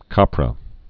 (käprə, kăprə)